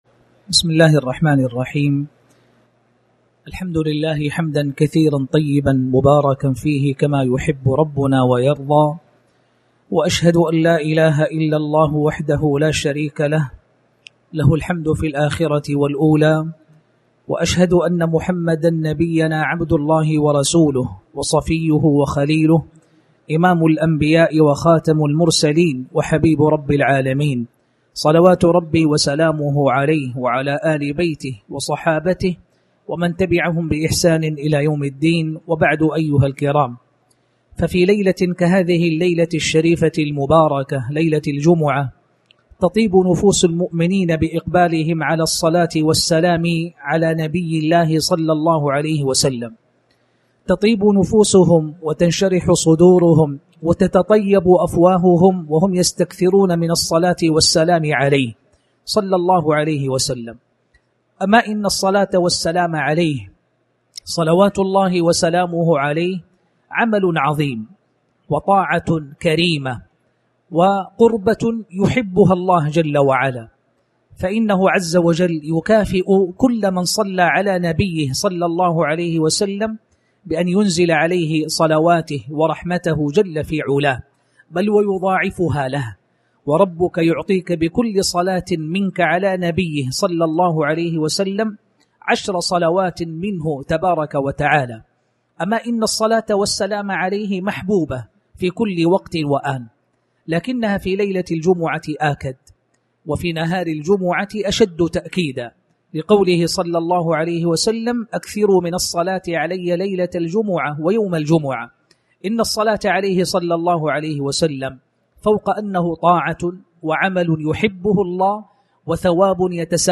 تاريخ النشر ٢٢ محرم ١٤٣٩ هـ المكان: المسجد الحرام الشيخ